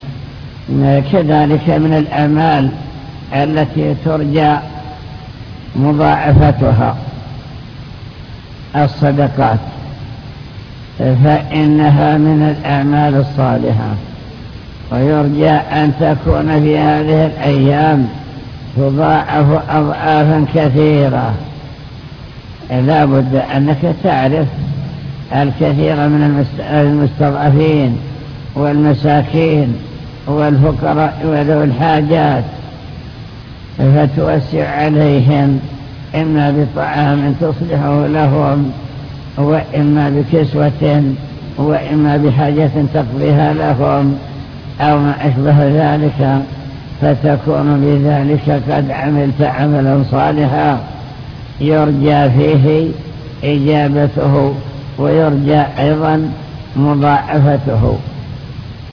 المكتبة الصوتية  تسجيلات - لقاءات  اللقاء المفتوح